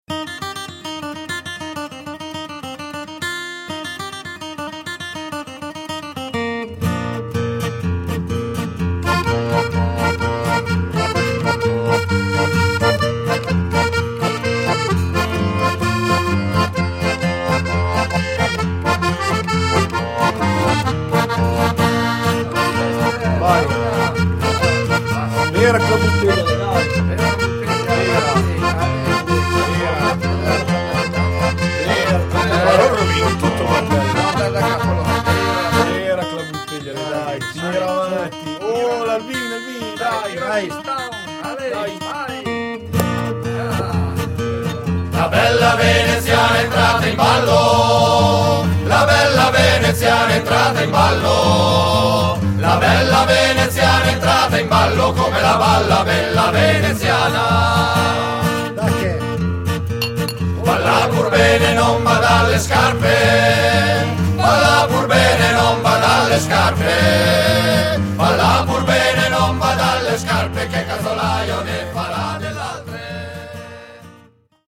Dalle musiche da ballo della tradizione popolare emiliana,
DEMO mp3 - Frammenti brani registrazione live